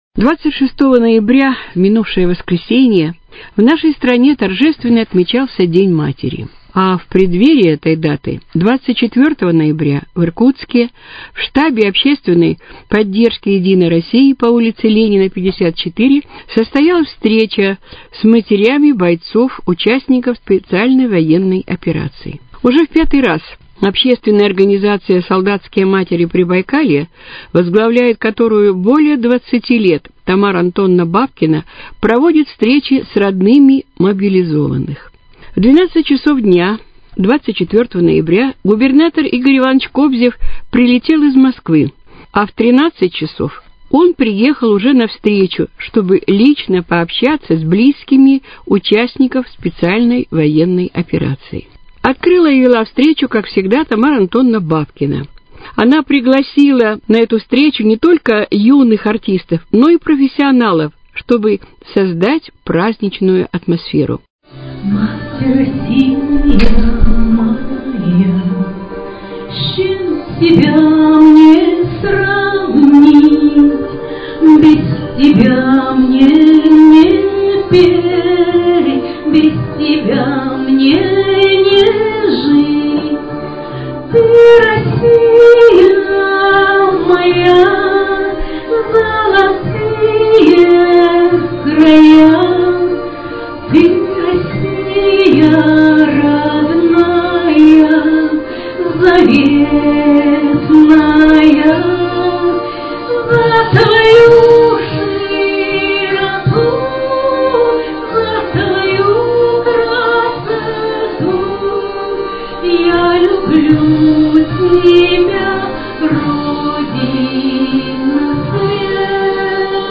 Репортаж ко Дню матери
24 ноября, в канун Дня матери, в штабе общественной поддержки «Единой России» состоялась встреча губернатора Иркутской области Игоря Ивановича Кобзева и мэра Иркутска Руслана Николаевича Болотова с матерями бойцов – участников специальной военной операции.